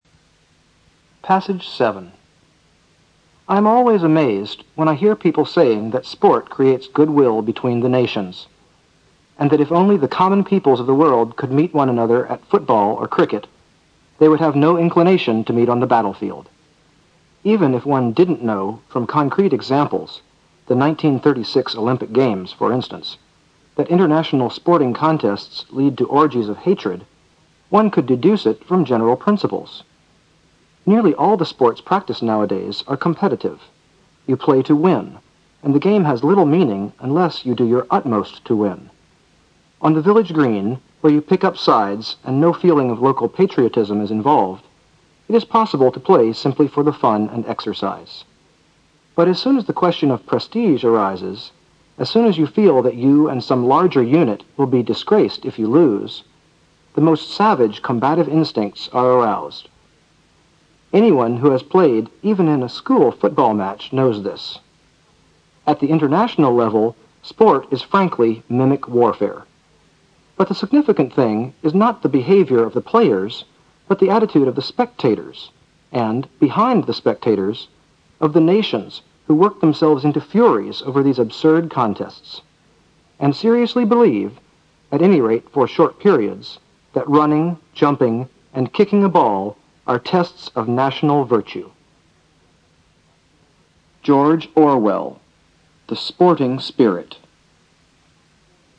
新概念英语85年上外美音版第四册 第7课 听力文件下载—在线英语听力室